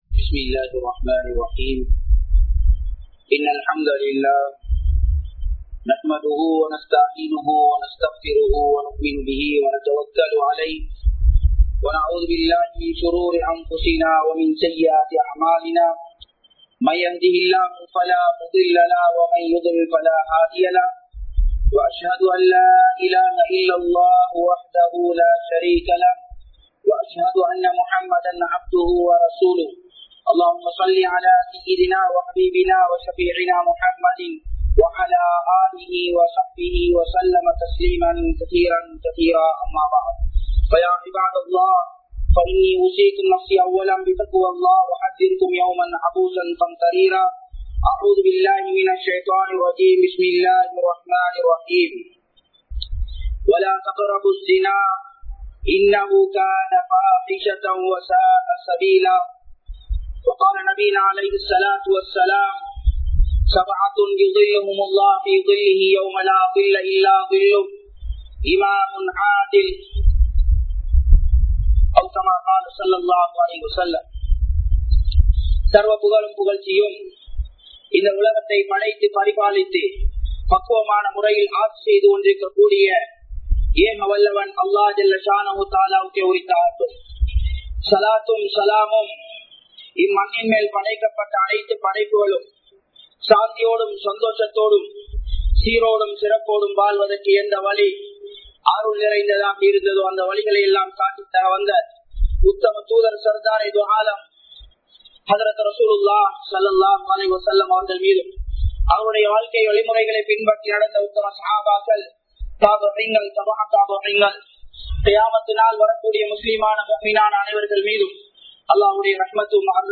Paavangalin Vilaivuhal (பாவங்களின் விளைவுகள்) | Audio Bayans | All Ceylon Muslim Youth Community | Addalaichenai
Majmaulkareeb Jumuah Masjith